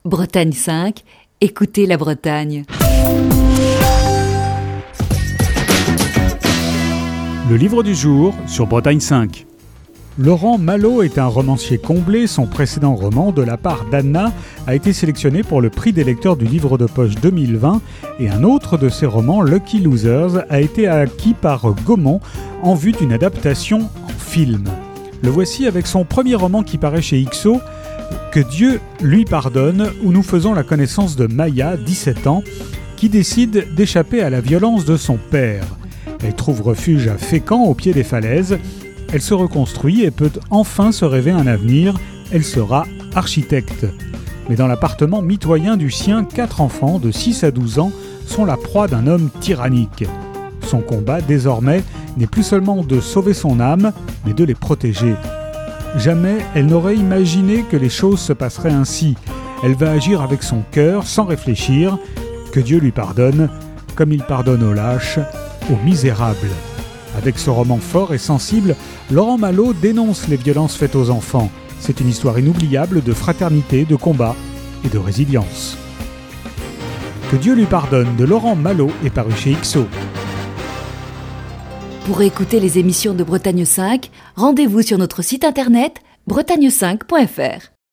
Chronique du 23 mars 2020.